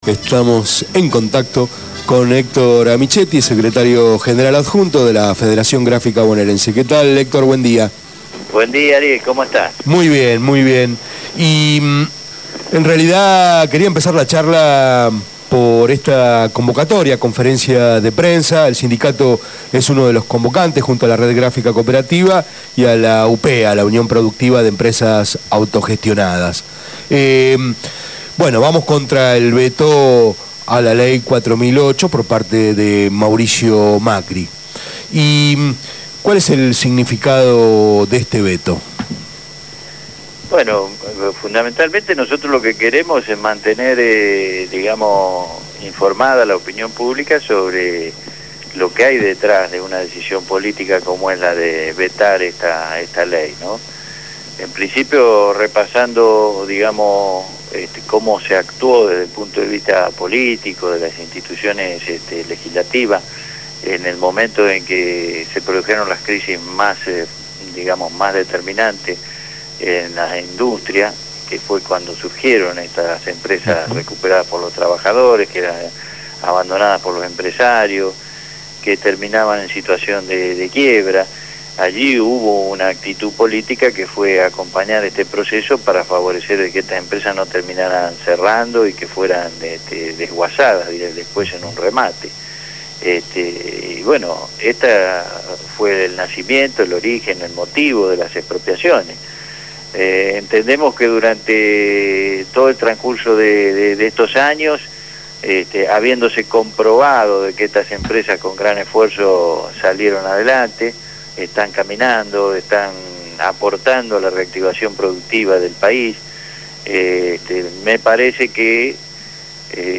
habló esta mañana con Punto de Partida.